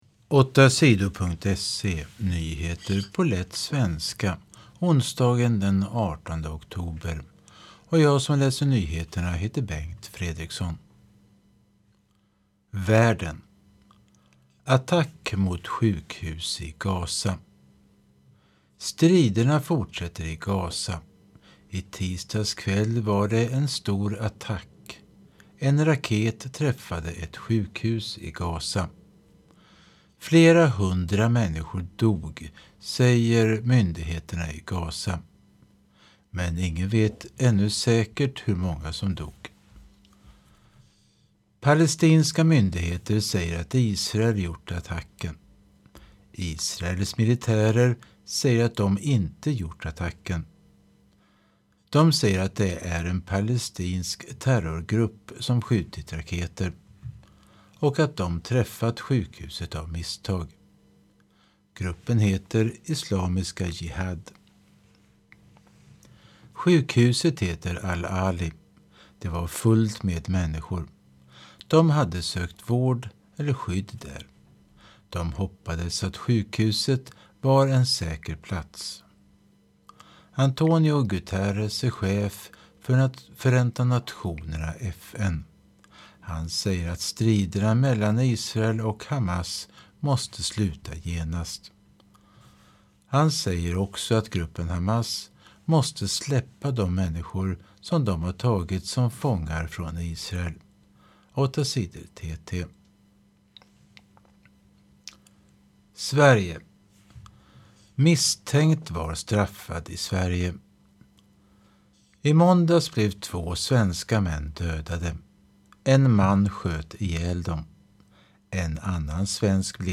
Nyheter på lätt svenska den 18 oktober